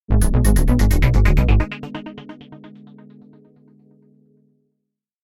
alert bad bonus collect ding evil game notification sound effect free sound royalty free Sound Effects